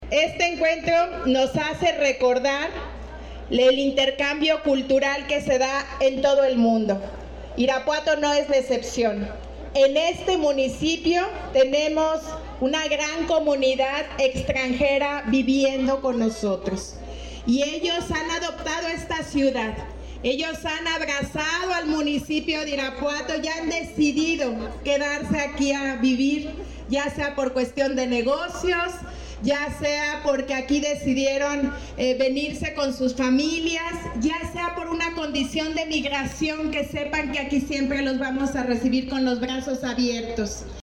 Irapuato, Gto. 13 de octubre del 2024 .- Las familias disfrutaron de una tarde llena de sabor, color y tradición con una edición más de la Fiesta de las Cultura 2024, esta vez en el Centro Histórico de la ciudad.